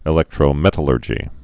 (ĭ-lĕktrō-mĕtl-ûrjē)